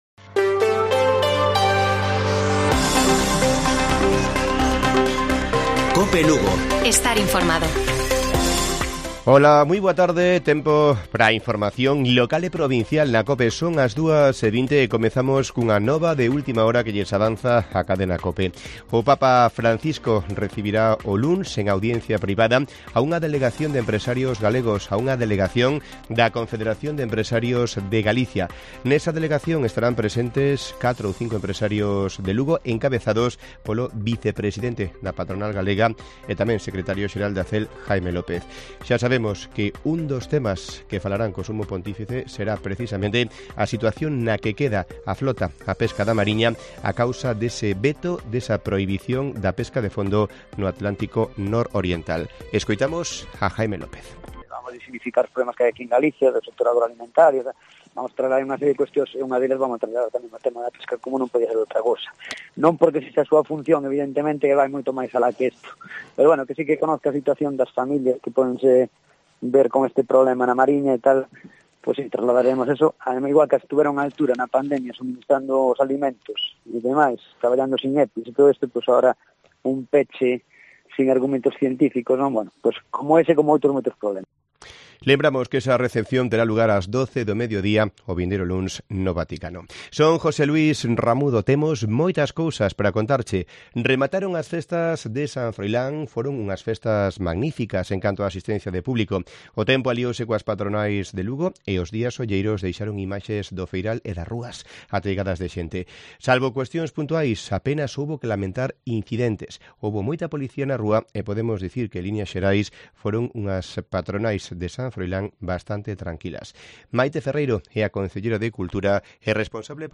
Informativo Mediodía de Cope Lugo. 13 de OCTUBRE. 14:20 horas